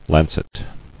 [lan·cet]